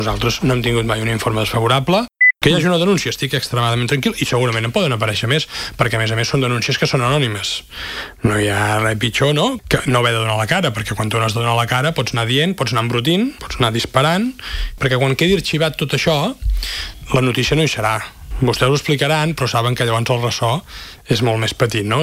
A l’entrevista del magazine A L’FM i +, l’alcalde ha dit que està “extremadament tranquil” amb la gestió que s’ha fet des del govern perquè, a més, i segons ha recalcat, tots els expedients de contractació estan avalats pels serveis tècnics de l’Ajuntament.